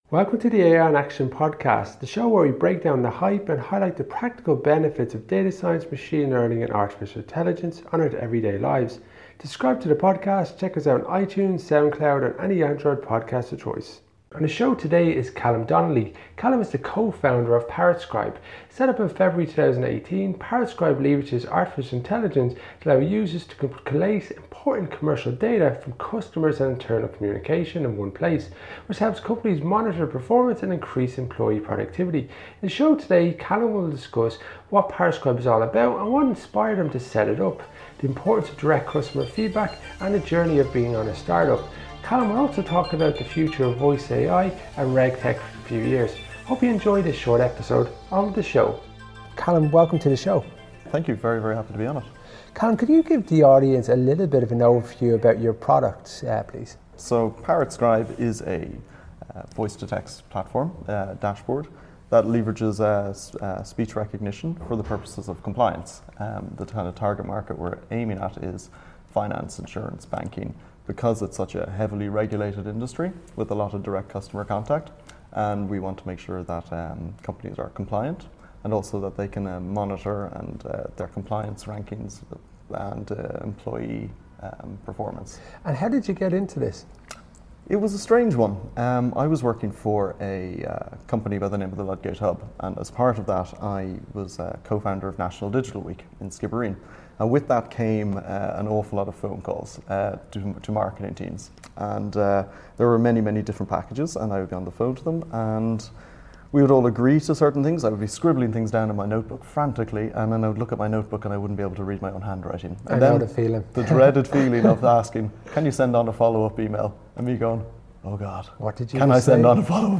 Our goal is to share with you the insights of technologists and data science enthusiasts to showcase the excellent work that is been done within AI in Ireland.